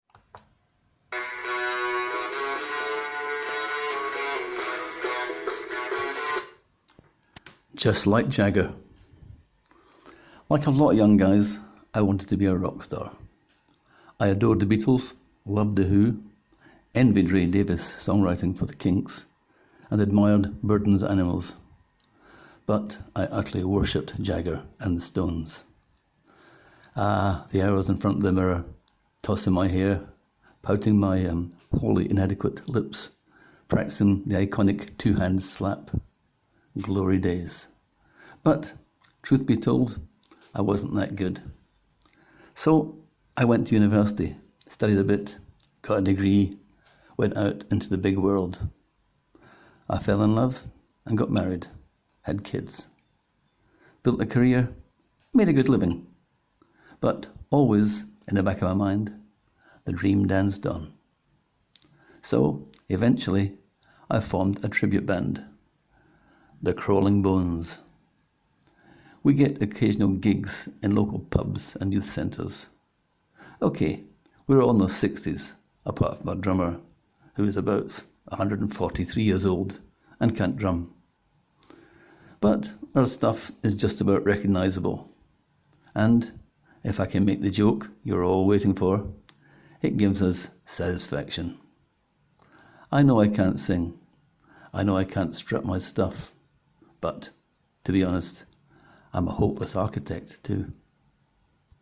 Click here to hear the author read the tale (or at least listen to the first 5 seconds!):